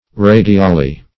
Search Result for " radiale" : The Collaborative International Dictionary of English v.0.48: Radiale \Ra`di*a"le\ (r[=a]`d[i^]*[=a]"l[-e]), n.; pl.